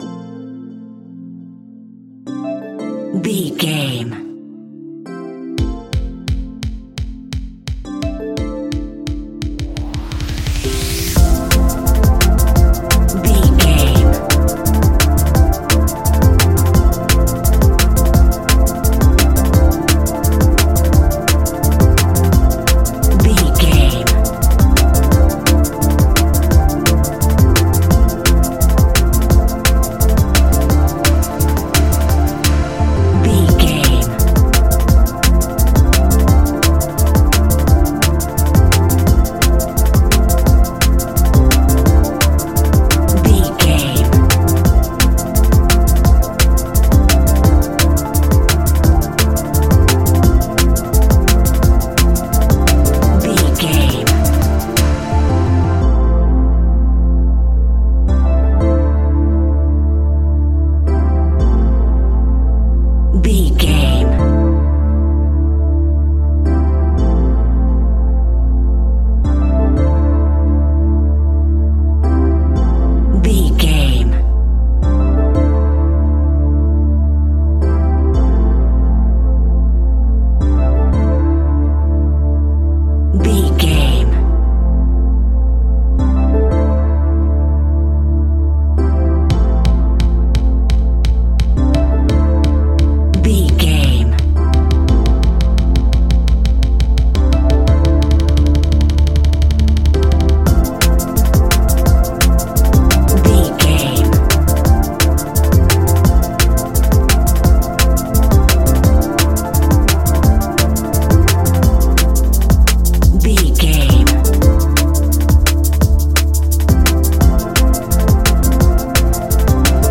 Ionian/Major
A♯
electronic
dance
techno
trance
synths
synthwave
instrumentals